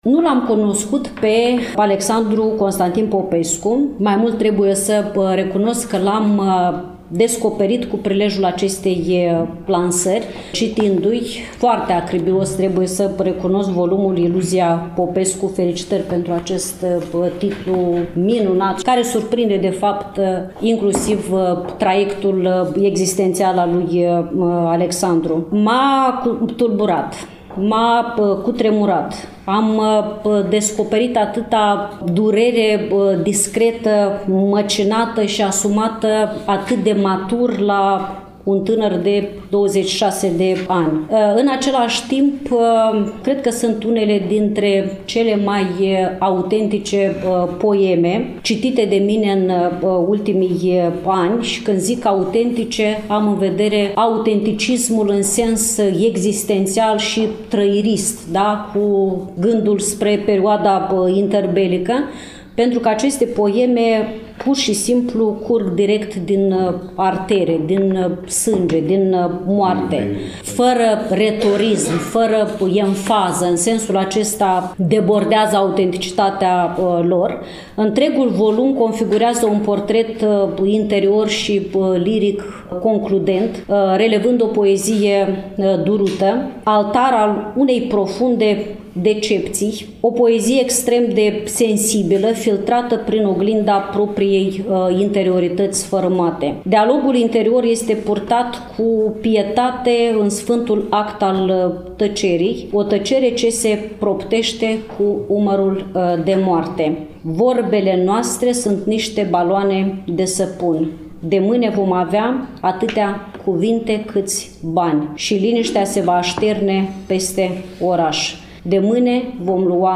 Cărțile au fost prezentate, la Iași, în ziua de joi, 21 noiembrie 2024, începând cu ora 14, în incinta sediului Editurii Junimea din Parcul Copou